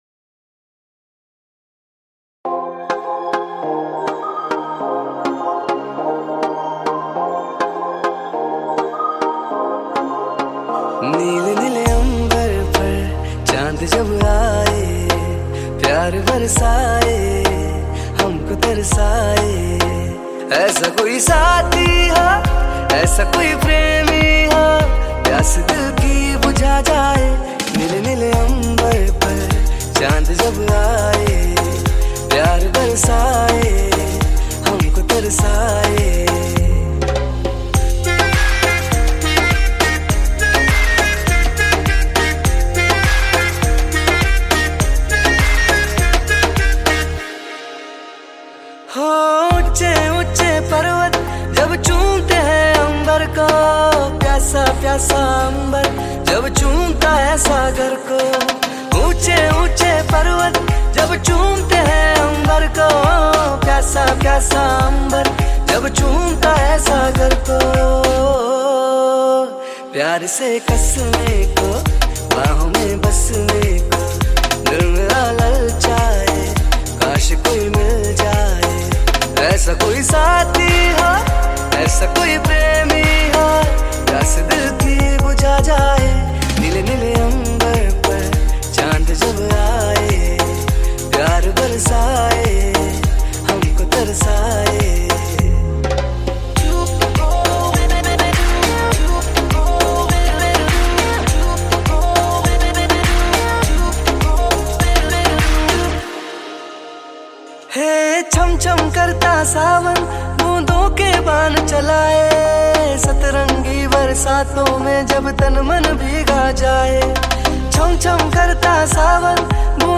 Category New Cover Mp3 Songs 2021 Singer(s